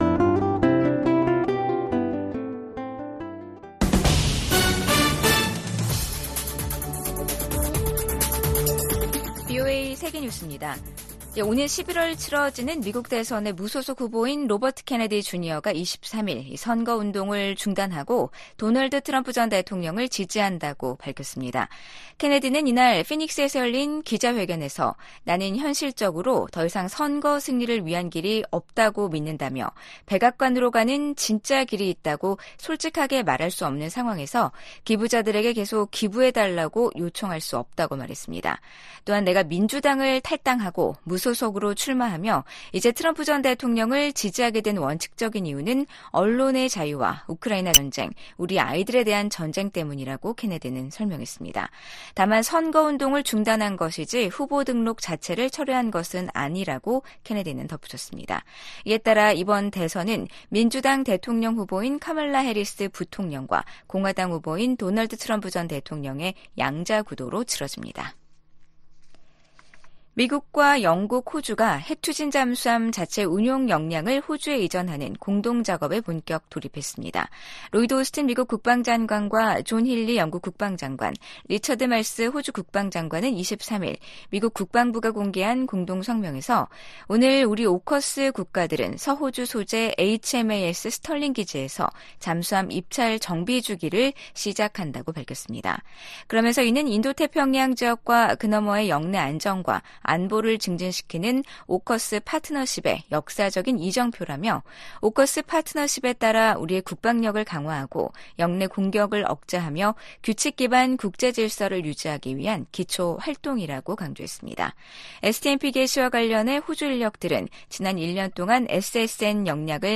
VOA 한국어 아침 뉴스 프로그램 '워싱턴 뉴스 광장' 2024년 8월 24일 방송입니다. 카멀라 해리스 미국 부통령이 민주당의 대선 후보 수락 연설에서 모든 미국인을 위한 대통령이 되겠다며 분열된 미국을 하나로 통합하겠다는 의지를 밝혔습니다. 북한과 중국, 러시아가 인접한 중국 측 지대에 길이 나고 구조물이 들어섰습니다. 김정은 국무위원장 집권 후 엘리트층 탈북이 크게 늘어난 것으로 나타났습니다.